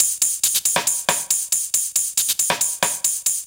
Birdie Hats 138bpm.wav